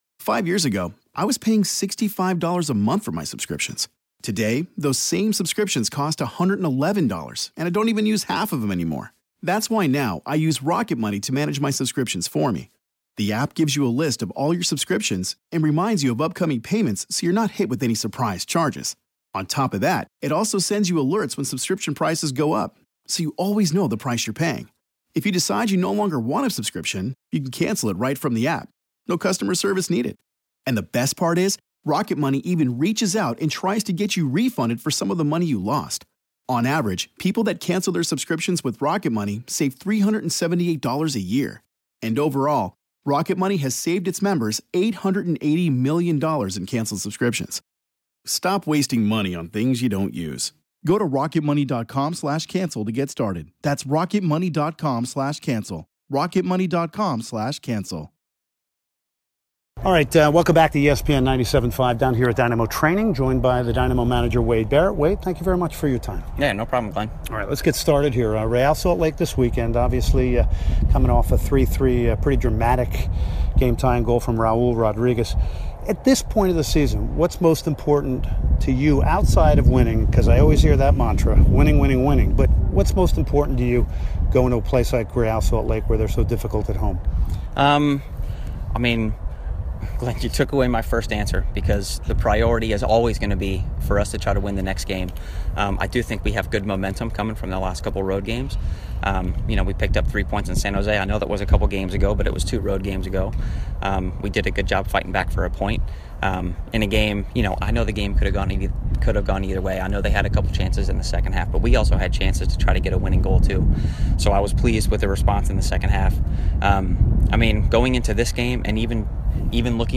09/14/2016 Wade Barrett Interview